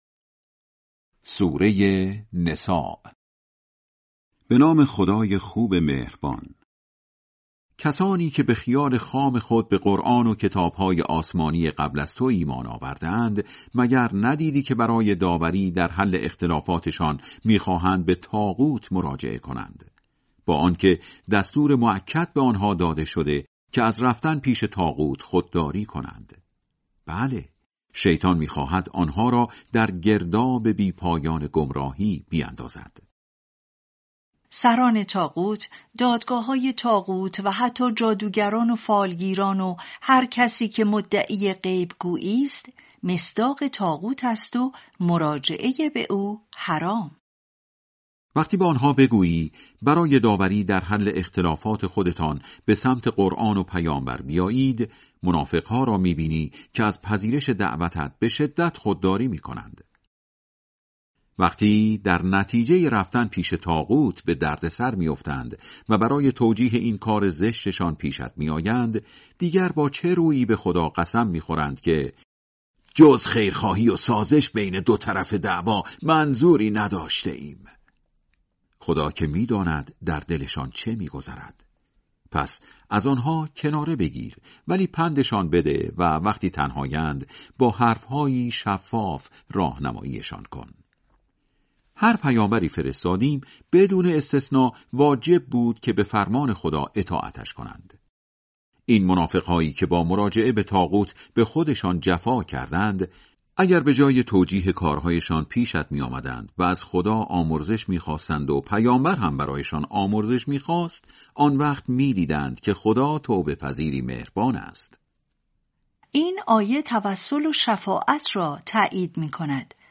ترجمه سوره(نساء)